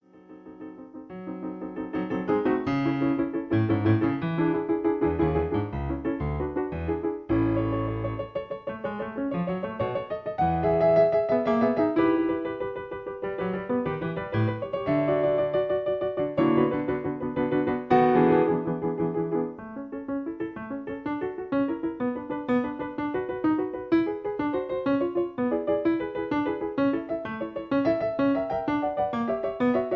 Original Key (A). Piano Accompaniment